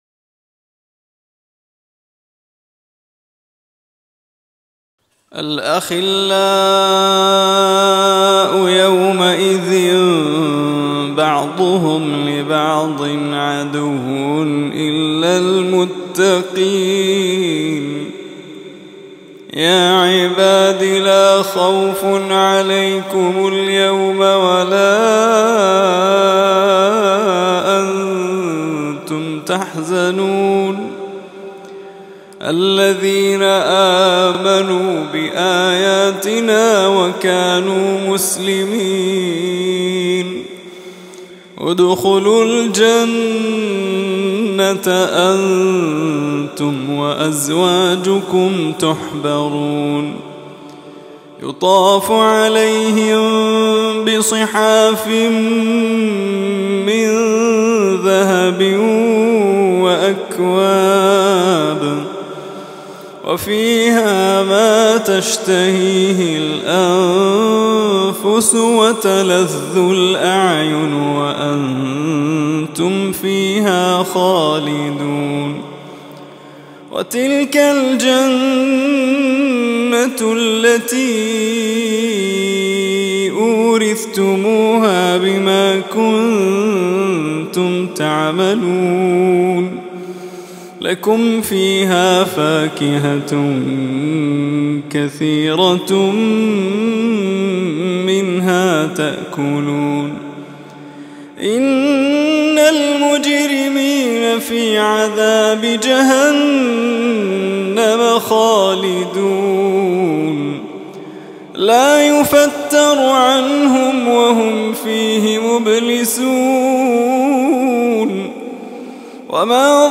شنّفوا آذانكم بهذه التلاوة المحبرة الخاشعة من سورة الزخرف